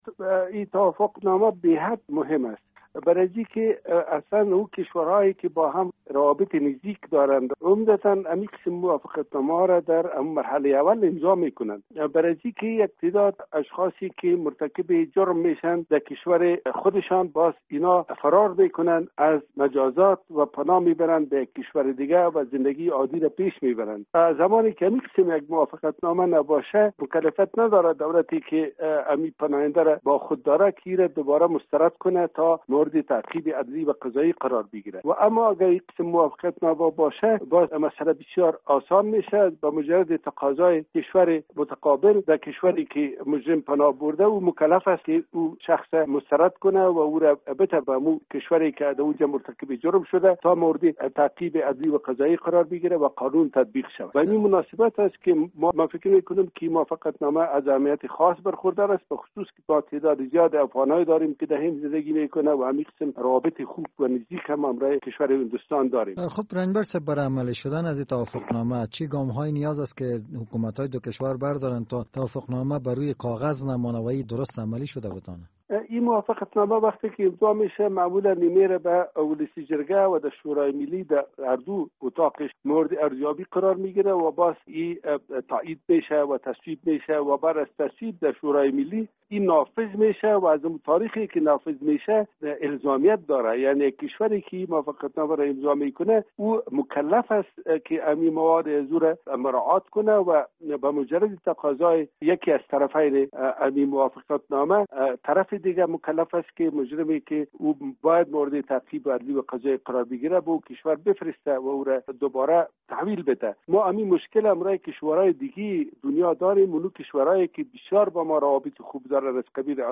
سفیر هند در کابل در مصاحبه اختصاصی با رادیو آزادی گفتهاست که در جریان سفر رئیس جمهور افغانستان به هند برخی توافقنامهها نیز میان...